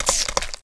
rifle_asidev2.wav